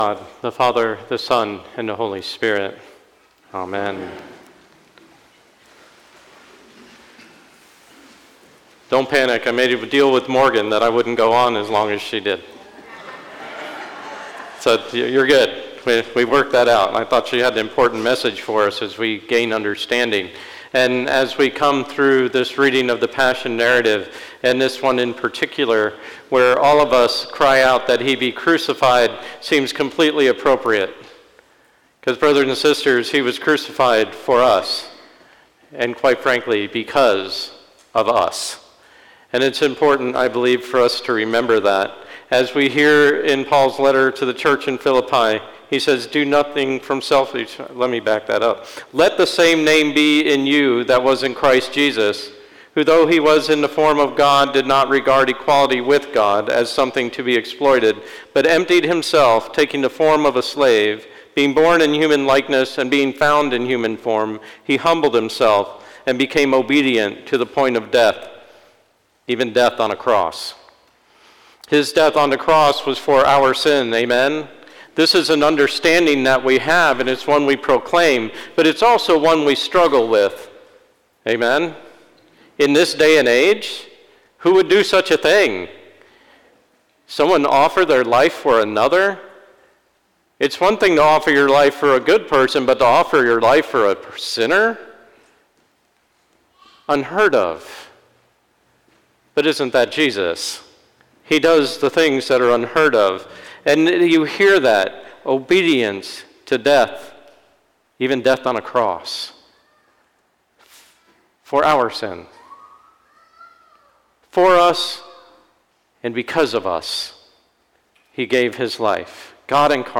Sermon 4/02/23 Sunday of the Passion: Palm Sunday - Holy Innocents' Episcopal Church
Sermon 4/02/23 Sunday of the Passion: Palm Sunday